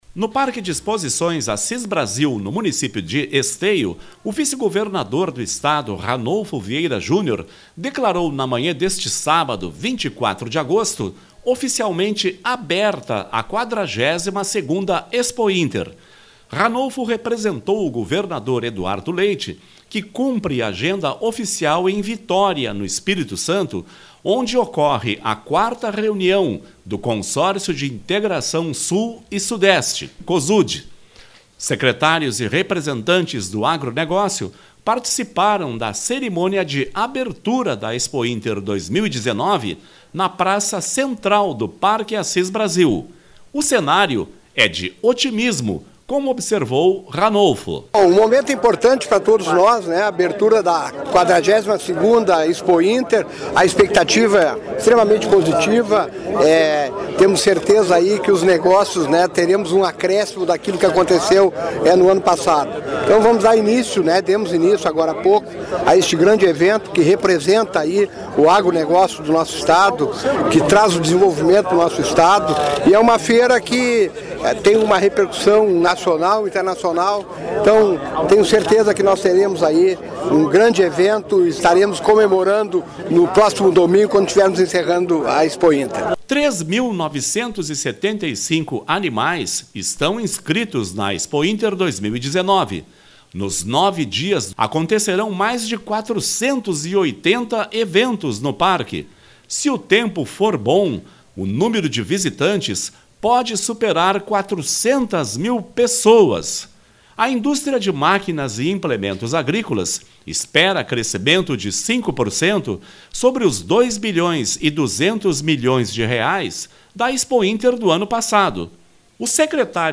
No Parque de Exposições Assis Brasil, em Esteio, o vice-governador do Estado, Ranolfo Vieira Júnior, declarou na manhã deste sábado (24), oficialmente aberta a 42ª Expointer. O cenário é de otimismo com expectativa de bons negócios.